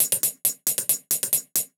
Index of /musicradar/ultimate-hihat-samples/135bpm
UHH_ElectroHatD_135-05.wav